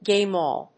アクセントgáme áll＝gáme and gáme